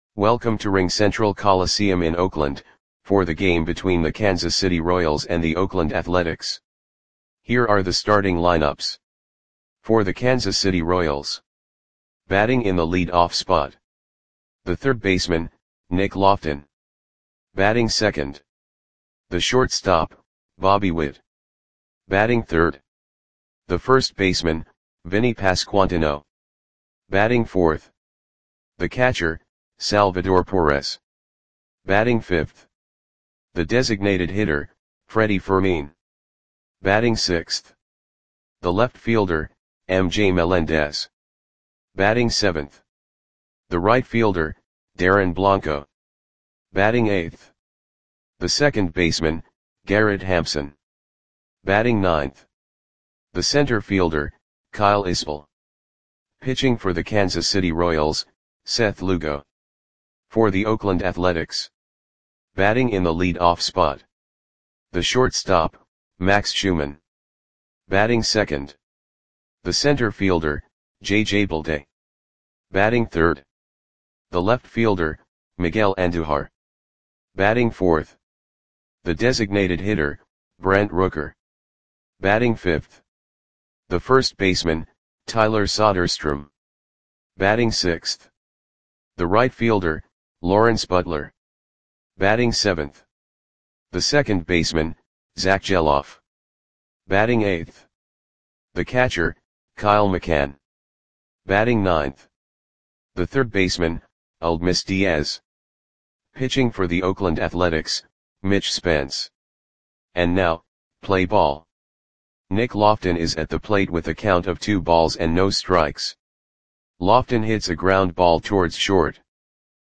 Audio Play-by-Play for Oakland Athletics on June 20, 2024
Click the button below to listen to the audio play-by-play.